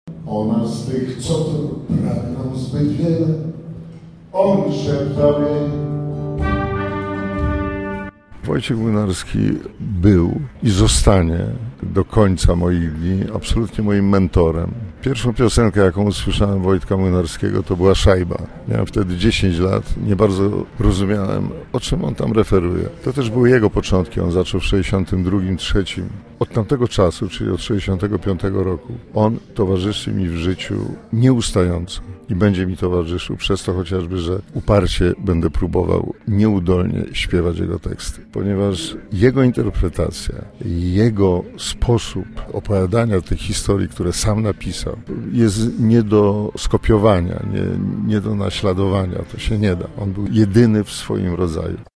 Tak mówił nam wtedy podczas wywiadu: